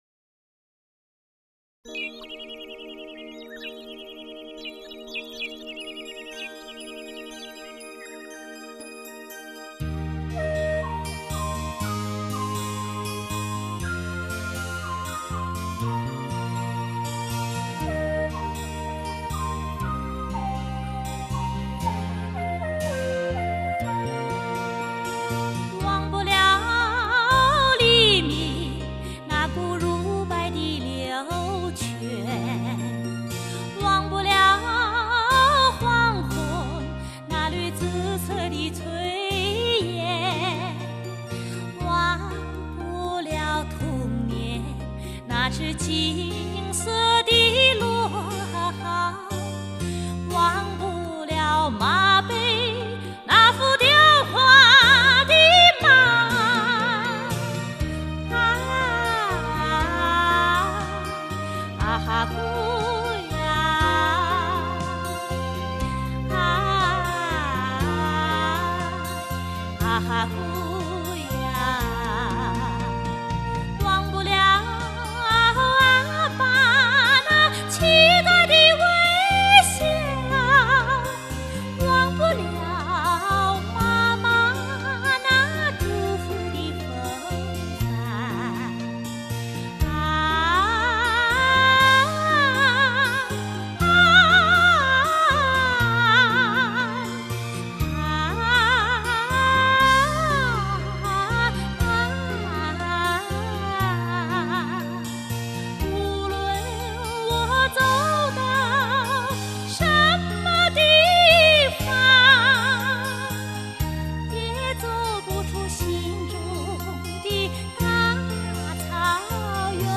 [音樂] 蒙古歌曲